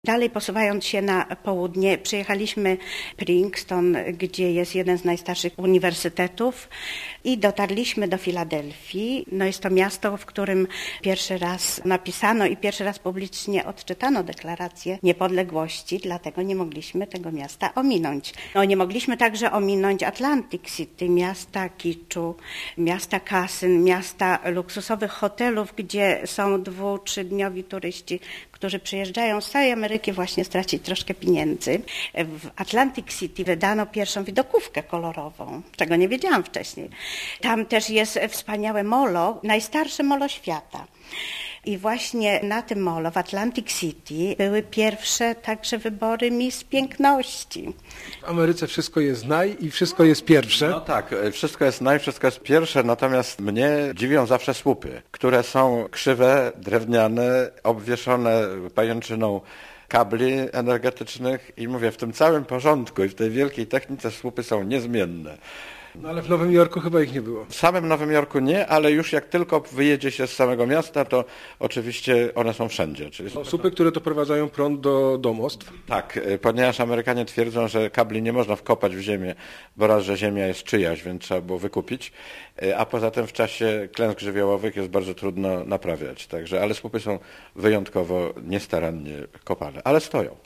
Tematy wypowiedzi radiowych: "Z GPS po 14 stanach", "Boston i okolice", "Nowy Jork", "Naj i... krzywe słupy", "Waszyngton", "Kosmiczne przygody" i "Podróż przez archipelag".